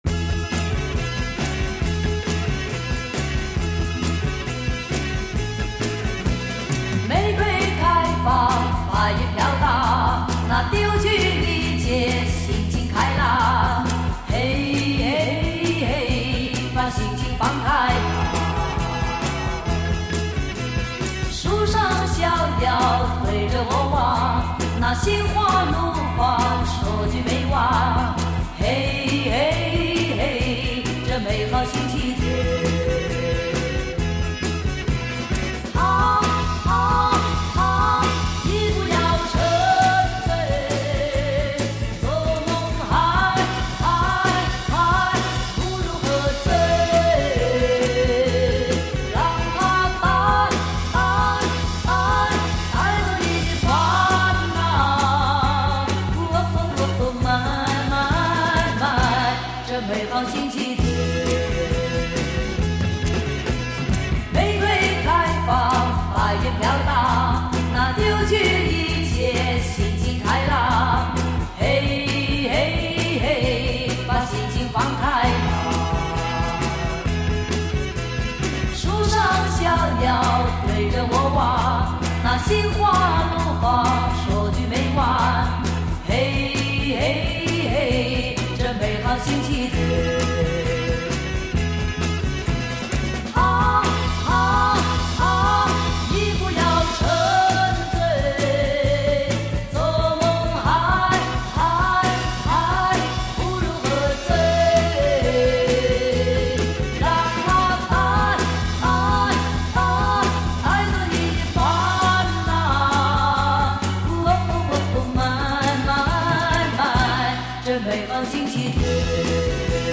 回味从小到大的磁性声音 惟有黑胶原版CD